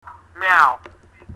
Meow